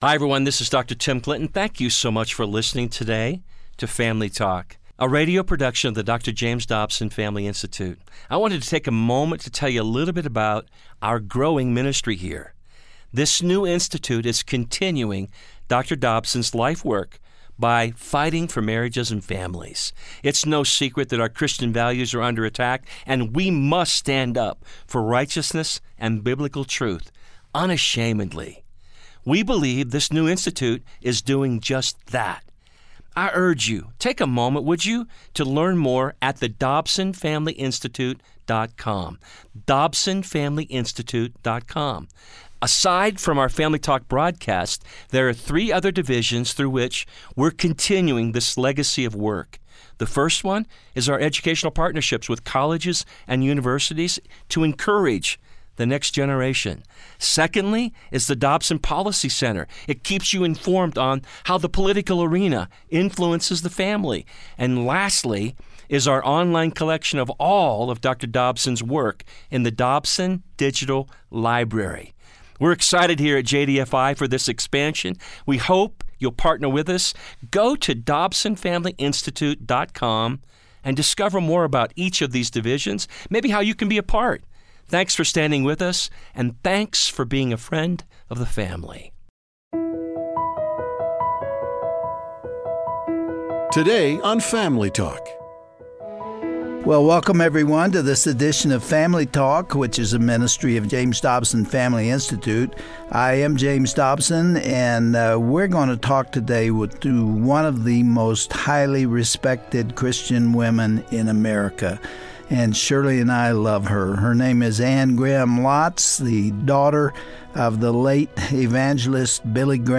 Host Dr. James Dobson
Guest(s):Anne Graham Lotz